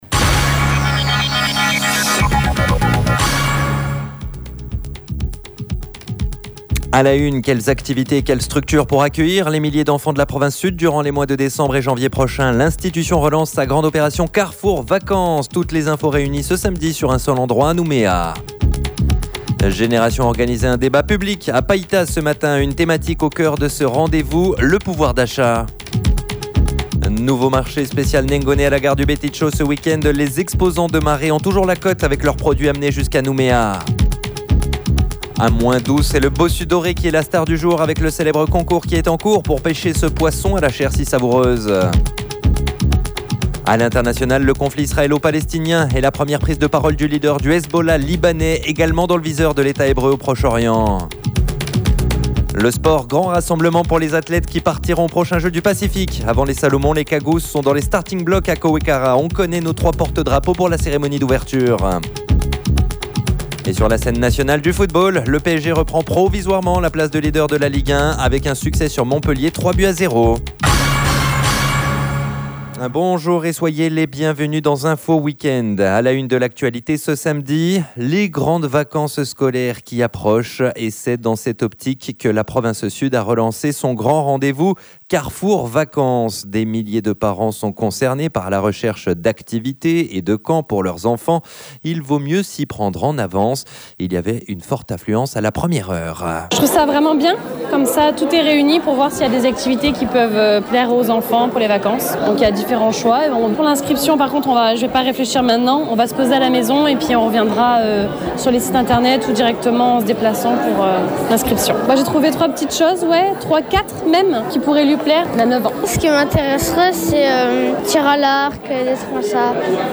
JOURNAL : INFO WEEK END SAMEDI MIDI